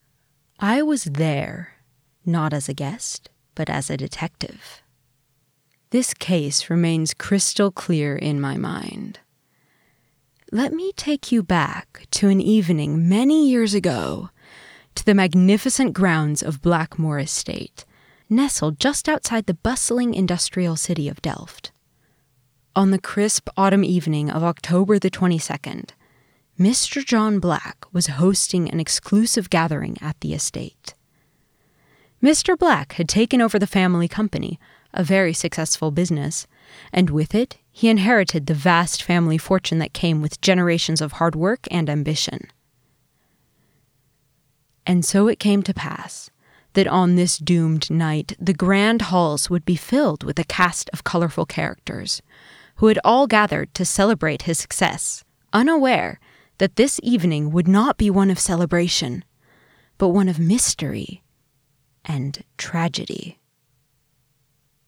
0.3_VO-new_Detective-intro-location_ENG.mp3